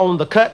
VOX SHORTS-1 0012.wav